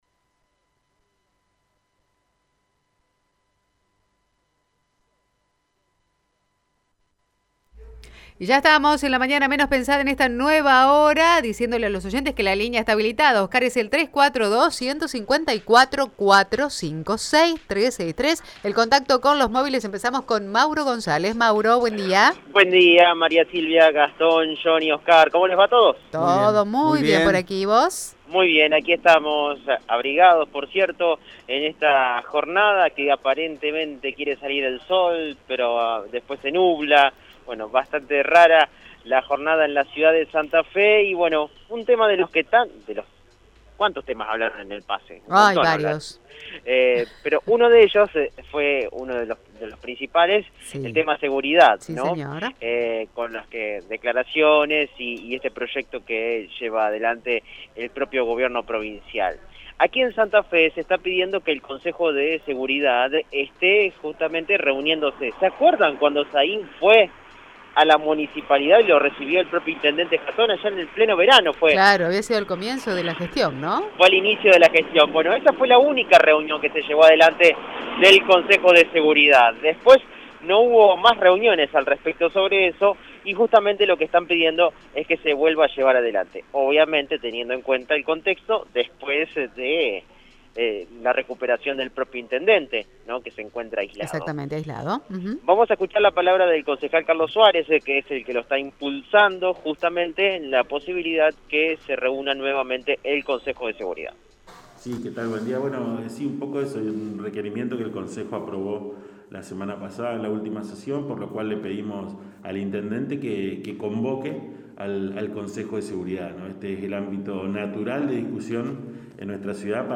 En diálogo con Radio Eme el edil, Carlos Suárez, manifestó que “La inseguridad es la otra pandemia que no disminuyó por el coronavirus”.